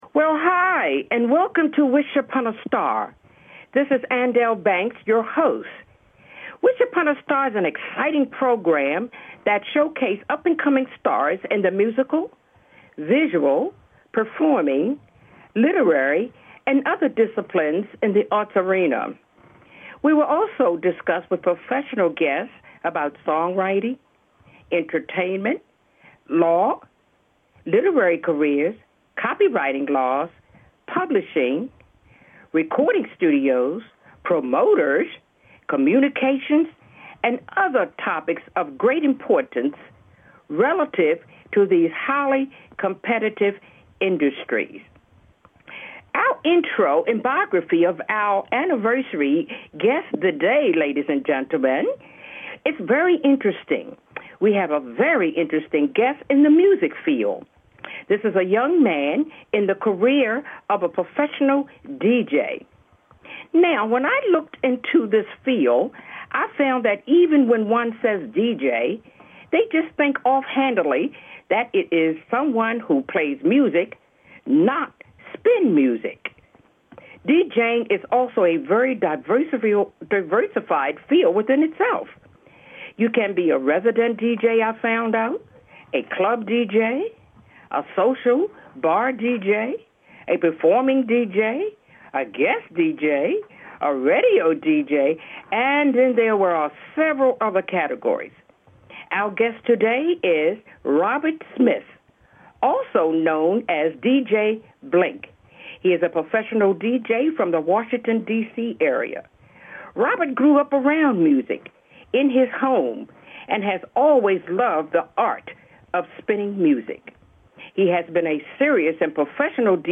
We will interview professional guests in the fields of entertainment law, copyrighting, studio recording, songwriting, publishing, and other topics in these highly creative and challenging fields."